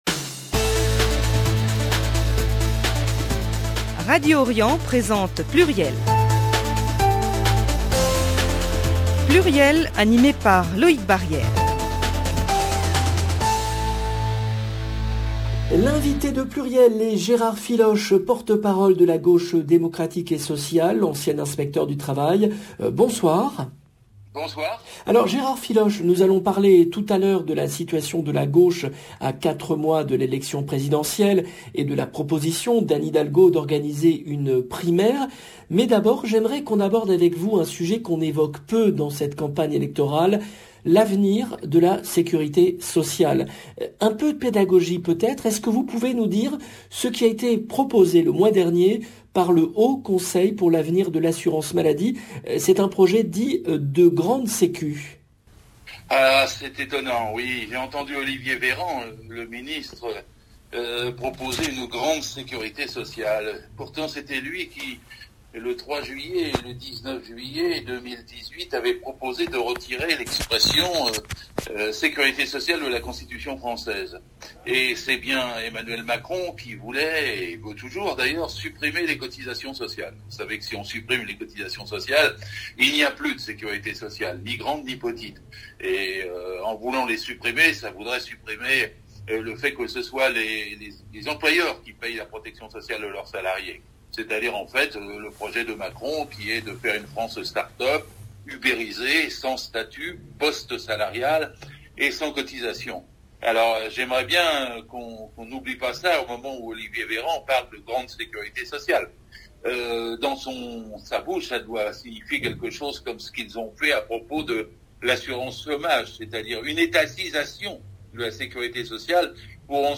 L’invité de PLURIEL est GÉRARD FILOCHE porte-parole de la GAUCHE DÉMOCRATIQUE ET SOCIALE, ancien inspecteur du travail